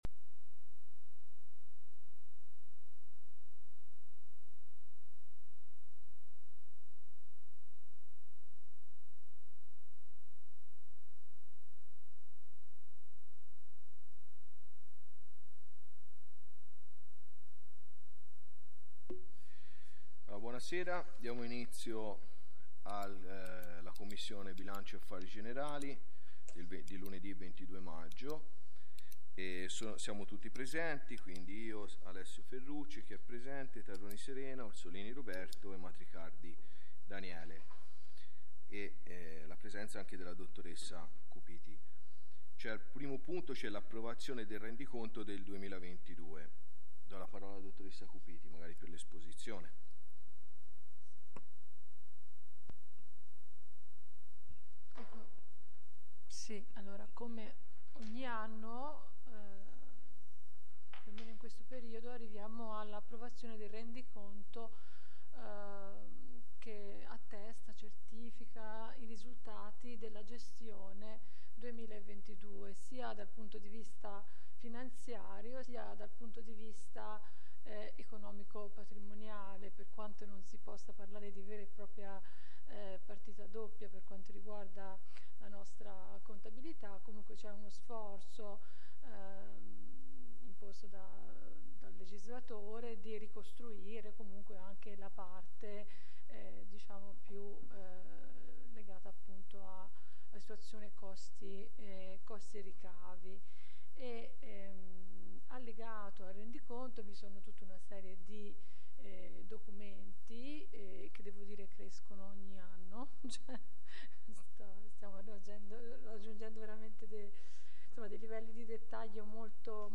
commissione bilancio e affari generali 22 maggio 2023